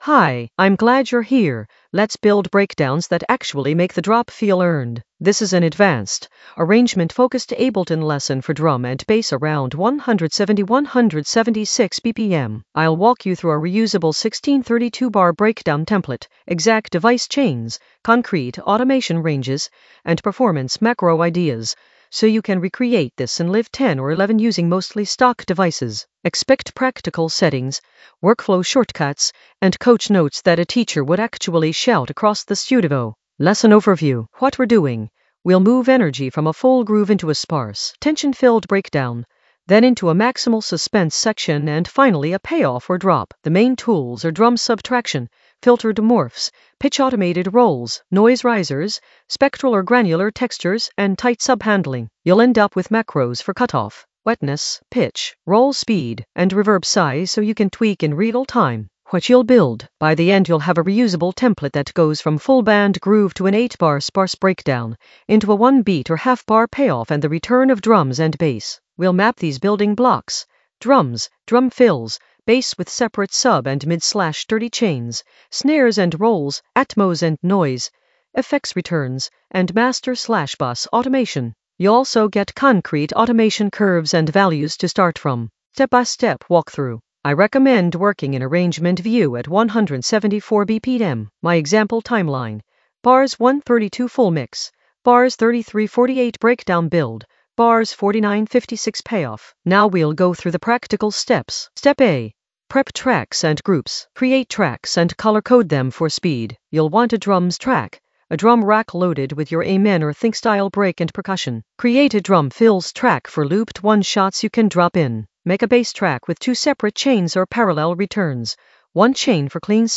An AI-generated advanced Ableton lesson focused on Building suspenseful breakdowns that pay off in the Arrangement area of drum and bass production.
Narrated lesson audio
The voice track includes the tutorial plus extra teacher commentary.
Teacher: energetic, clear, professional 🎧🔥